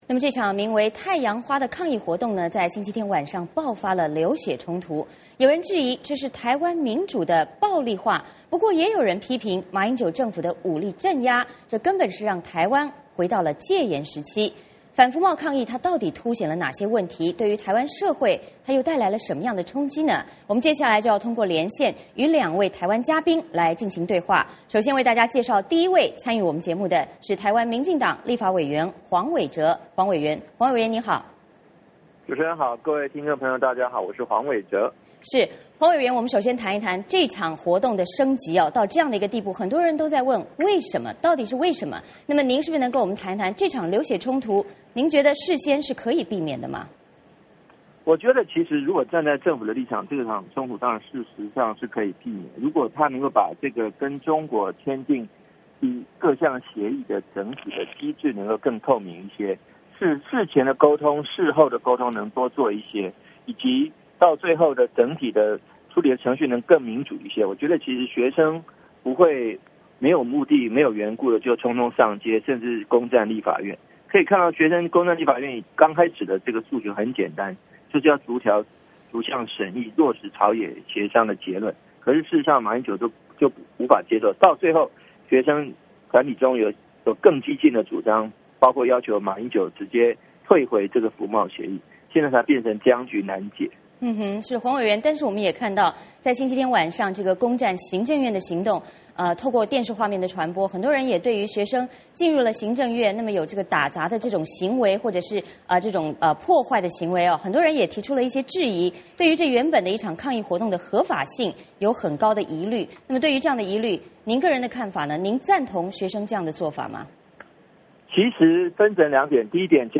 我们请两位台湾嘉宾